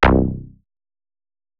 TM88 SYNTH BASS (3).wav